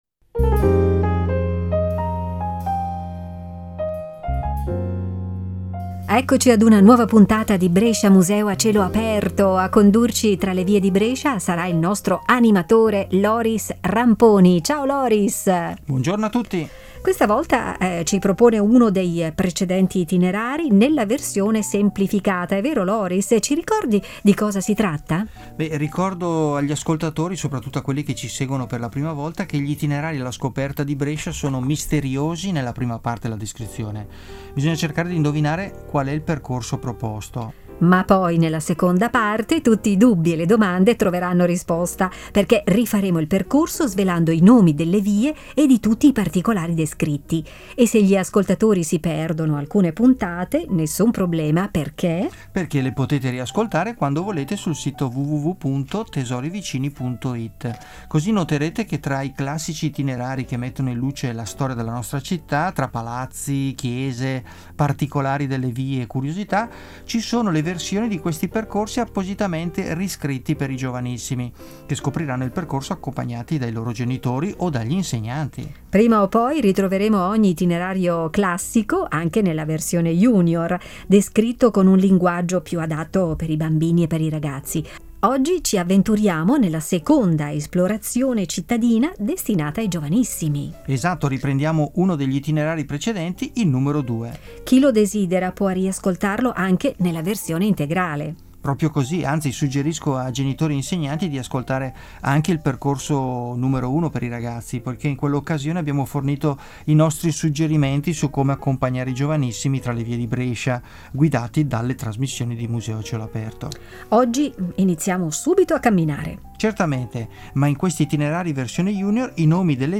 audio-guida junior e itinerari junior per passeggiare tra le vie di Brescia